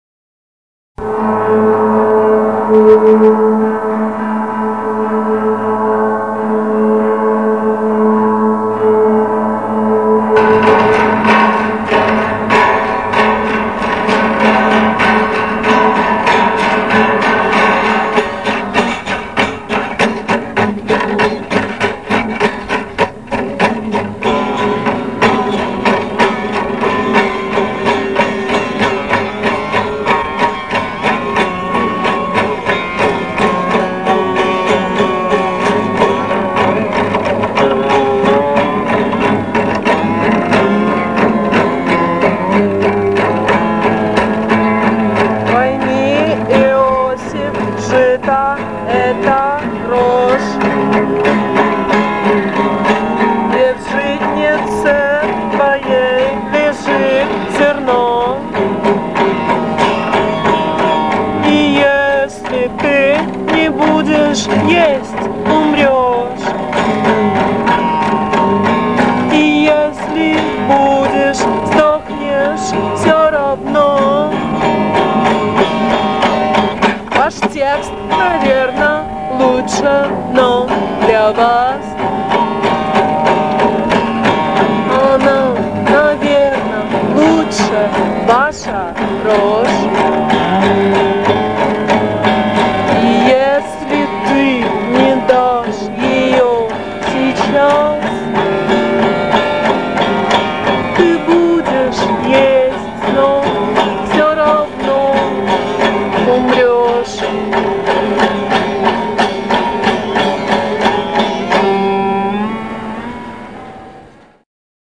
• Жанр: Бардрок
Маленькая песенка о вечном.